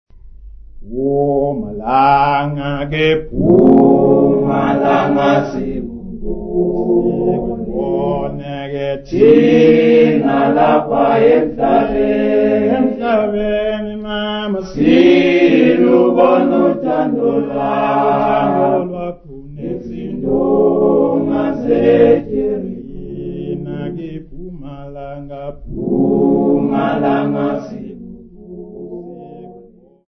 Folk music
Sacred music
Field recordings
Africa South Africa Lady Frere, Eastern Cape sa
Church choral hymn with unaccompanied singing. New Zulu Church service hymn.